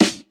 • HQ Hot Acoustic Snare Sound D# Key 63.wav
Royality free snare sample tuned to the D# note. Loudest frequency: 1637Hz
hq-hot-acoustic-snare-sound-d-sharp-key-63-18L.wav